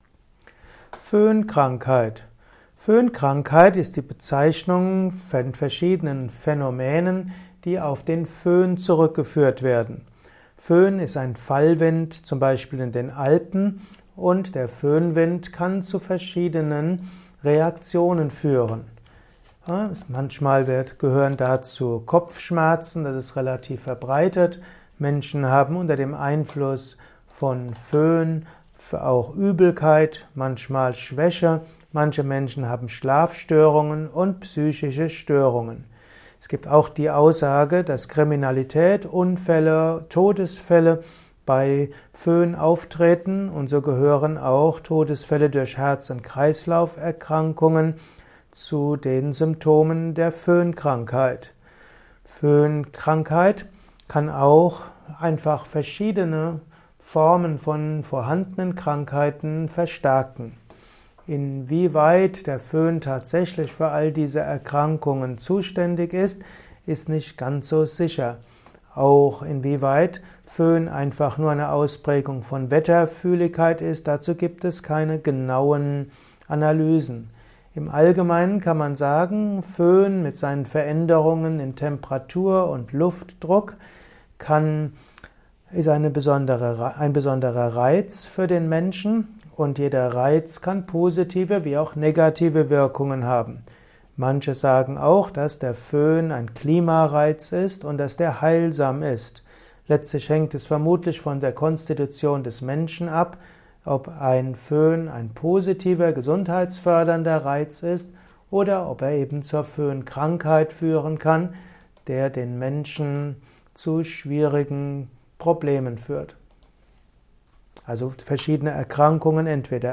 Vortragsaudio rund um das Thema Föhnkrankheit. Erfahre einiges zum Thema Föhnkrankheit in diesem kurzen Improvisations-Vortrag.